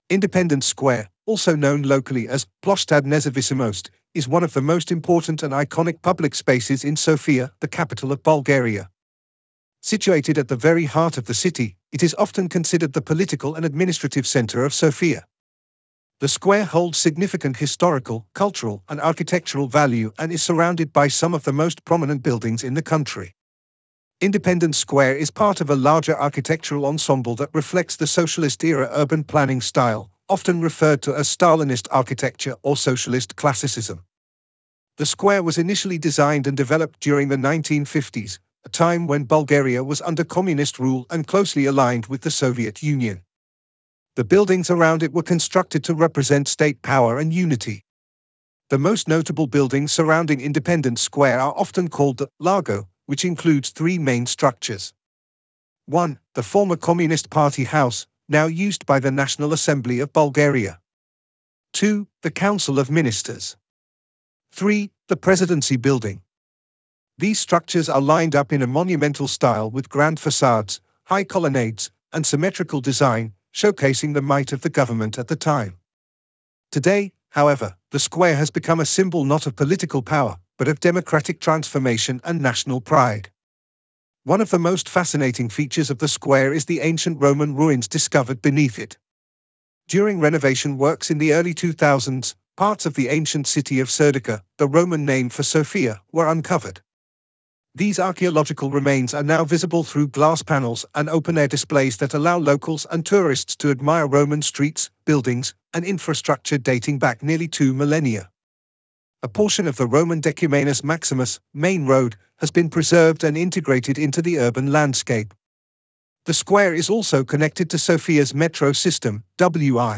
Listening and reading activity.
Text_to_Speech_(2).wav